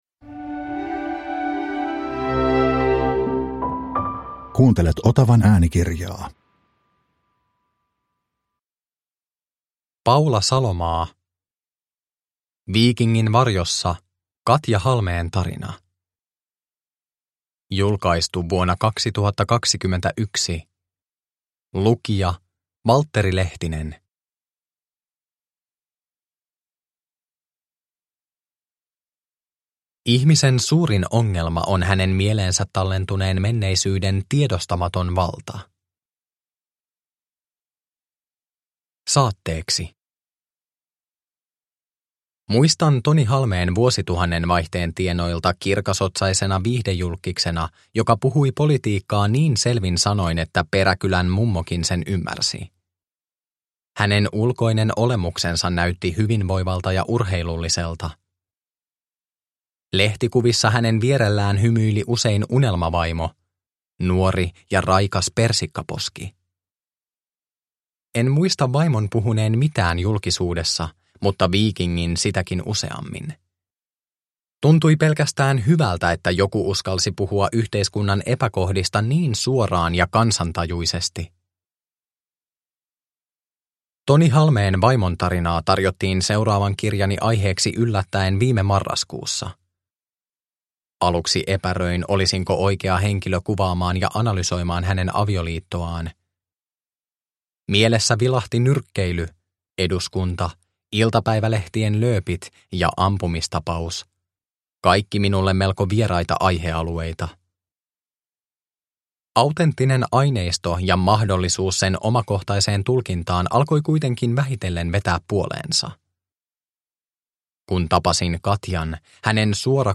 Viikingin varjossa – Ljudbok – Laddas ner